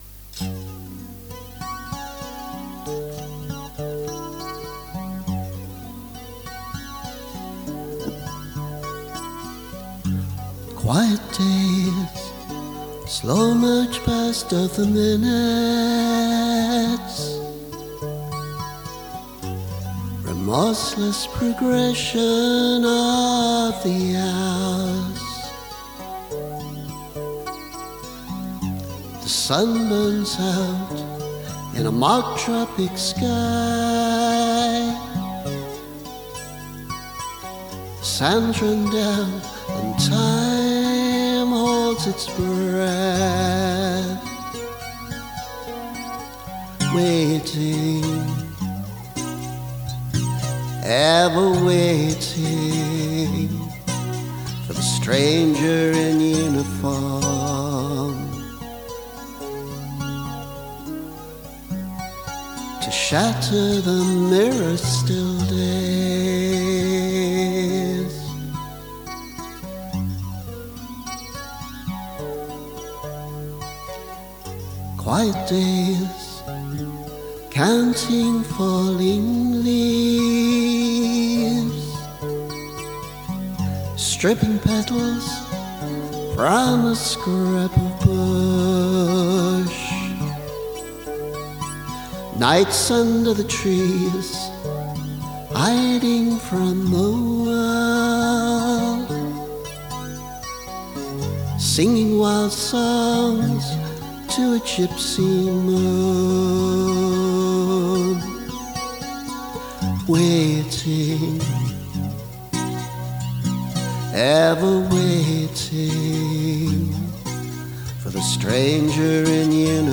Stranger in Uniform [demo]